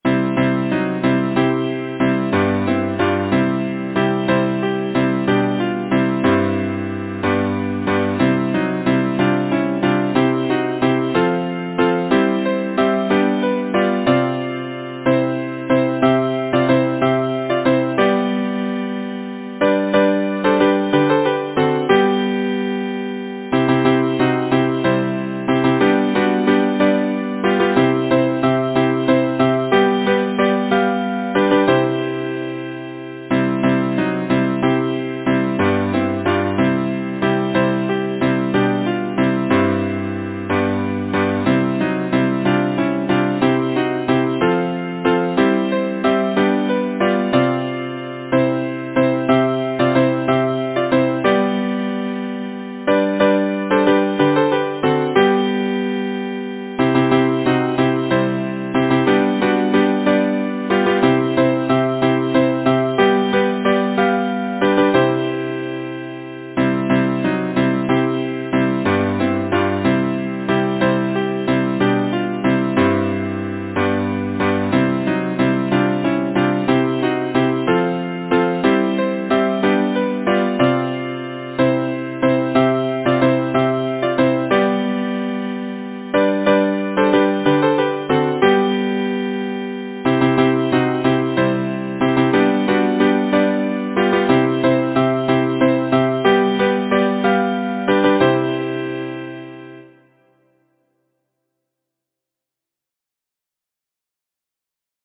Title: The merry maid Composer: Edward Roberts Lyricist: Number of voices: 4vv Voicing: SATB Genre: Secular, Partsong
Language: English Instruments: A cappella